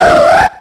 Cri d'Absol dans Pokémon X et Y.